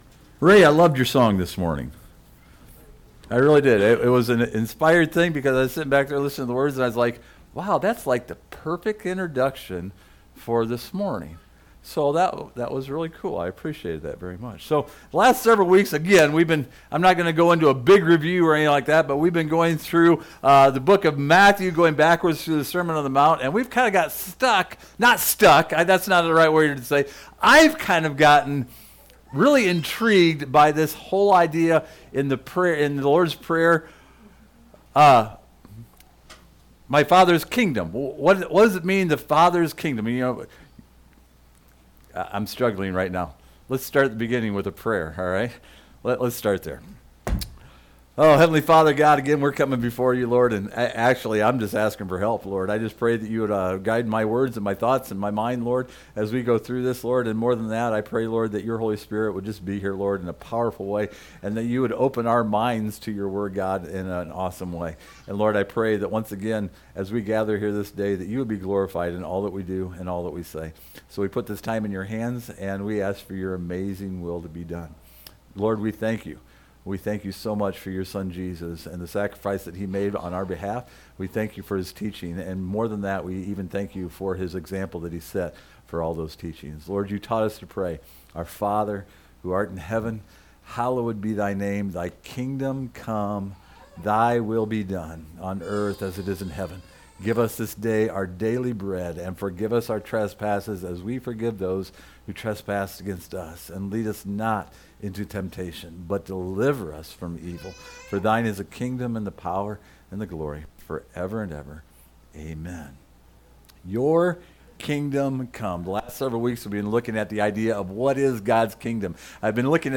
In this sermon the Kingdom of Heaven is equated with God’s will in our life. God’s will is worthy of seeking out and following.
Service Type: Sunday Morning